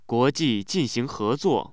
neutral